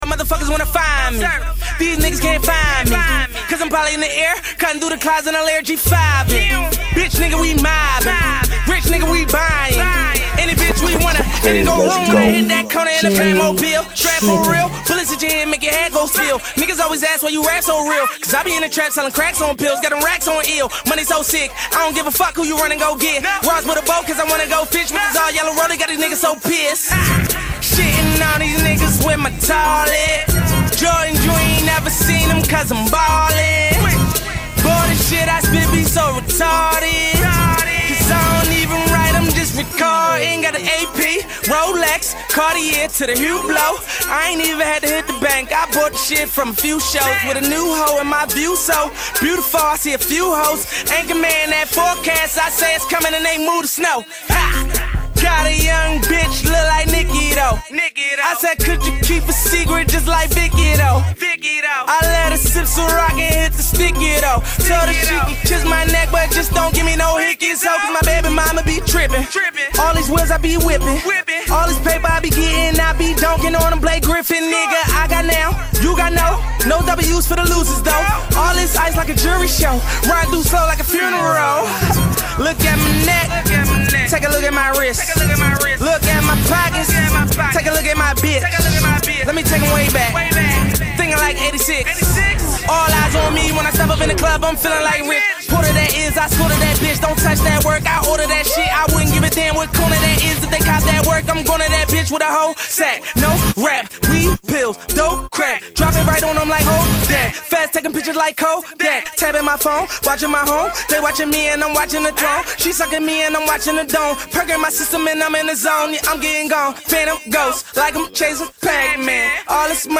HipHop 2010er